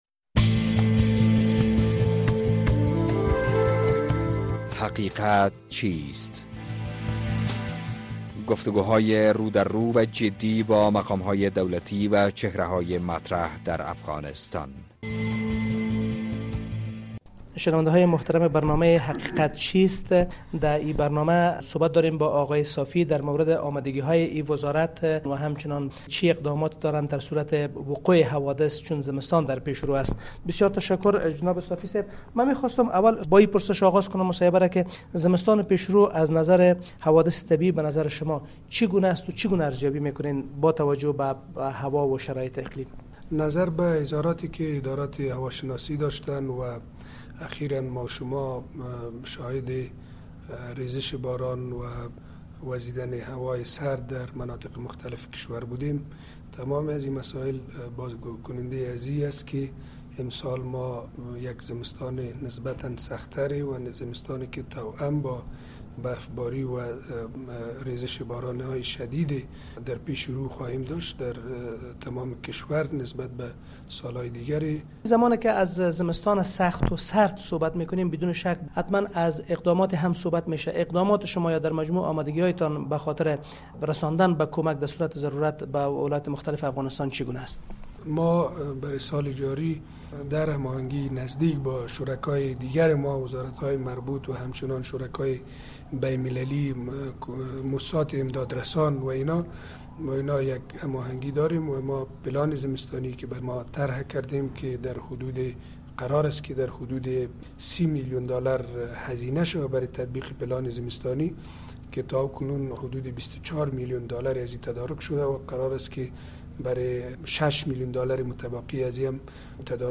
مصاحبه با مطیع‌الله صافی معین وزارت دولت در امور رسیدگی به حوادث
مصاحبه با مطیع‌الله صافی معین وزارت دولت در امور رسیدگی به حوادث است در مورد آمادگی‌های وزارت دولت در امور رسیدگی به حوادث در زمستان